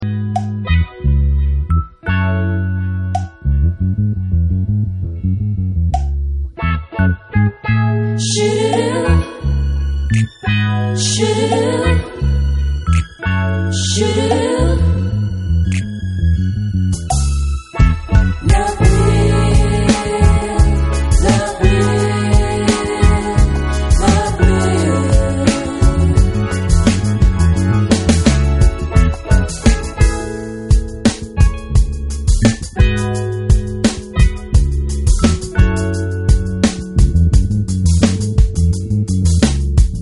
Karaoke lyrics and music will appear on your screen.